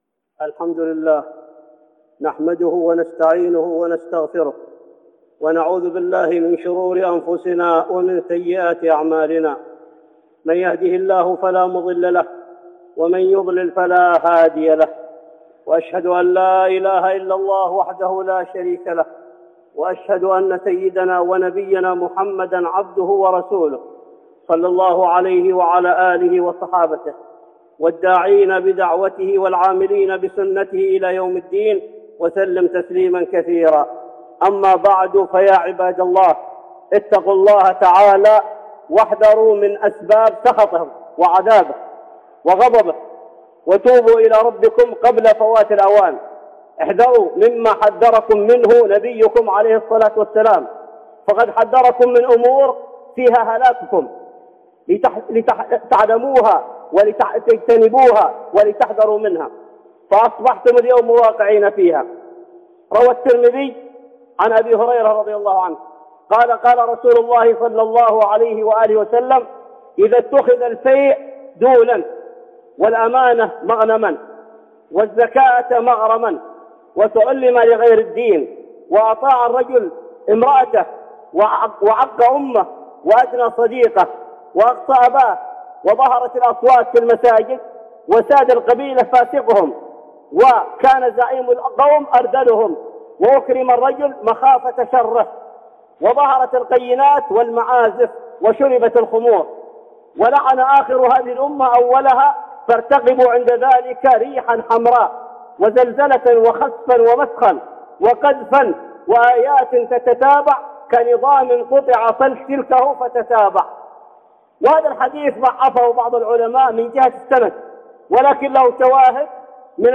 خطبة جمعة بعنوان (علامات قرب العذاب) والثانية (السوق السوداء)